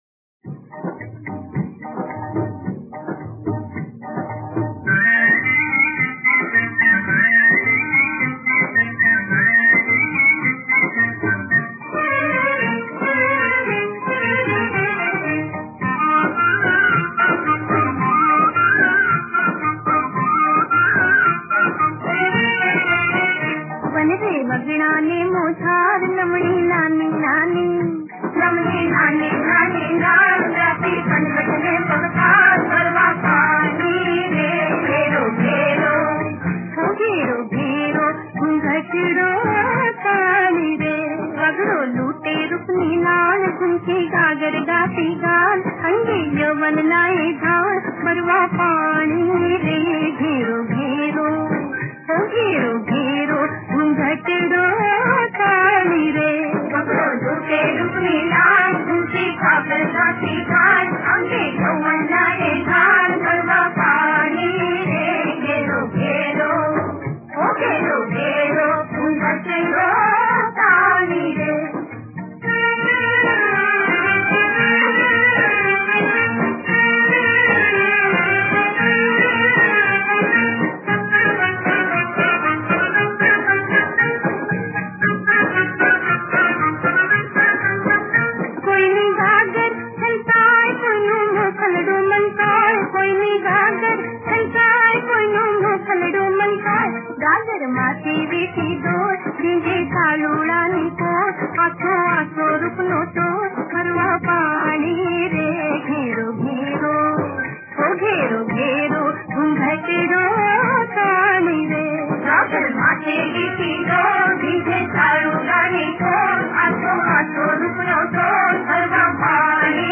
ગીત સંગીત લોક ગીત (Lok-Geet)